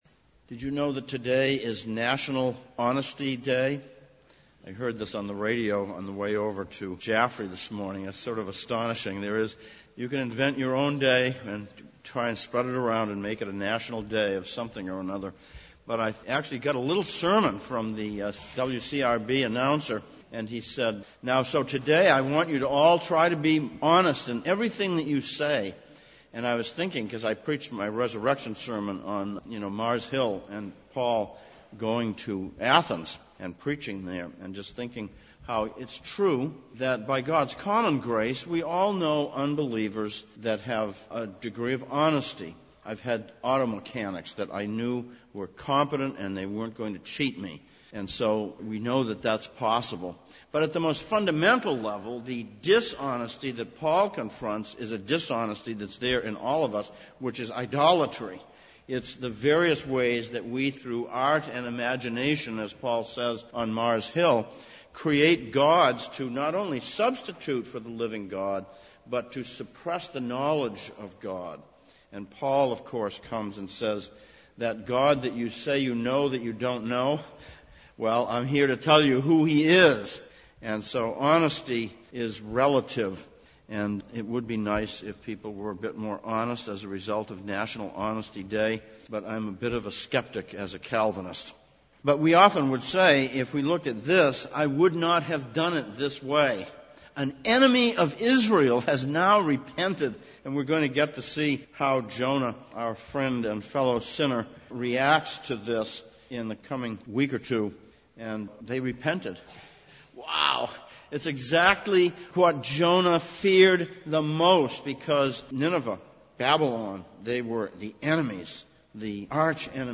2017 Passage: Jonah 3:1-10, Luke 19:1-9 Service Type: Sunday Evening Sermon text